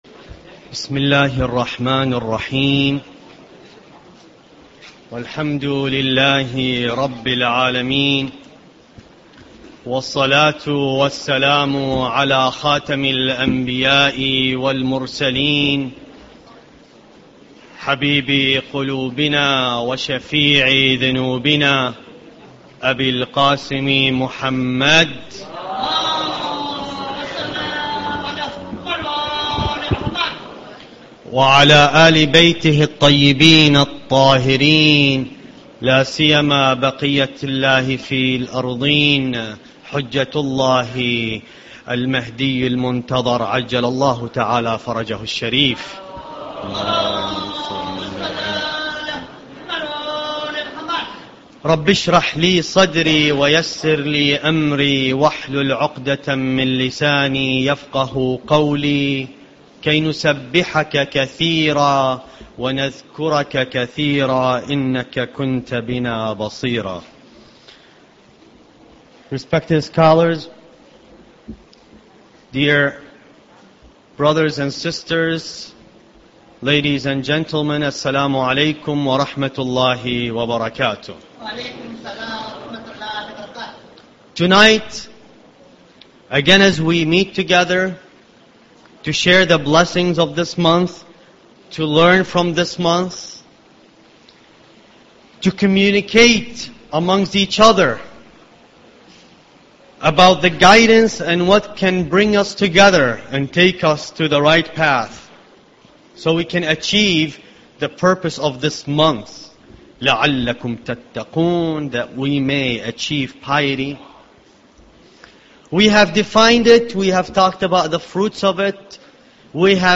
Ramadan Lecture 7